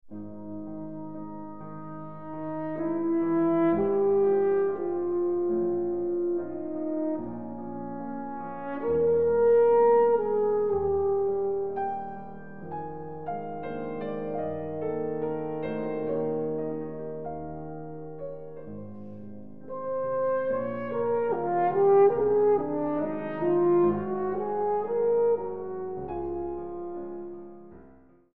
Horn
Piano
Iwaki Auditorium, ABC Southbank, Melbourne